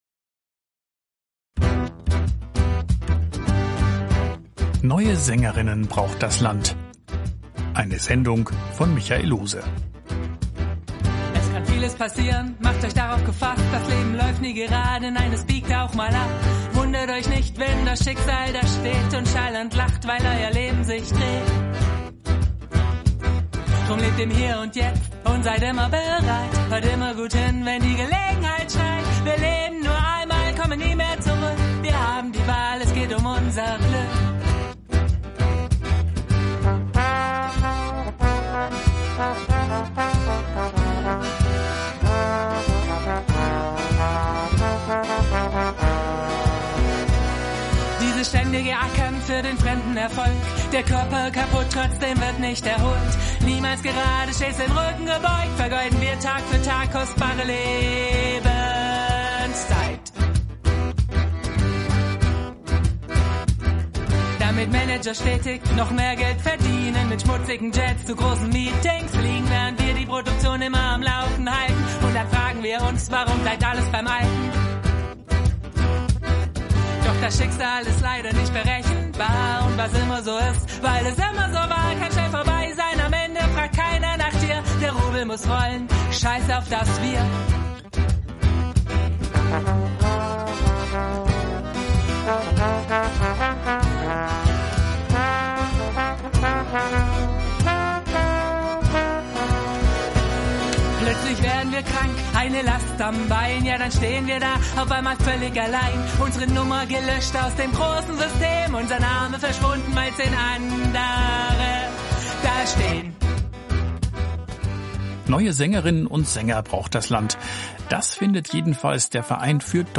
Radiointerview
Interview-Liederlounge.mp3